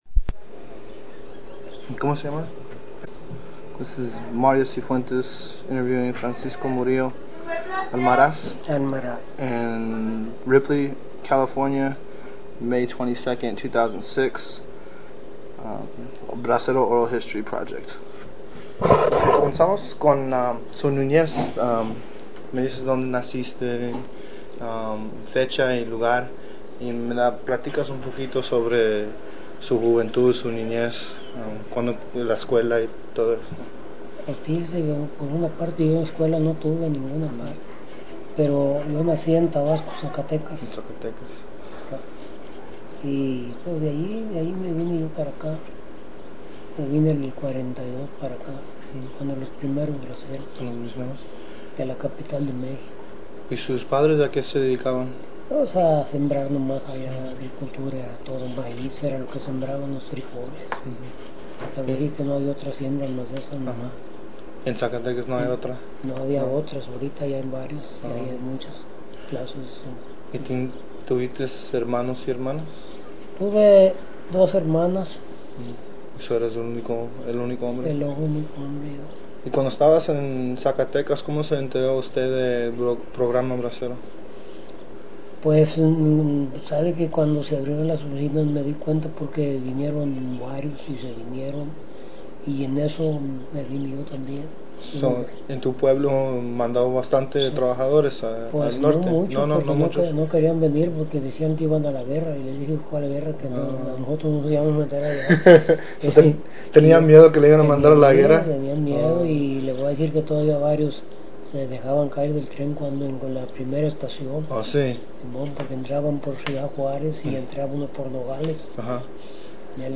Summary of Interview
Original Format Mini disc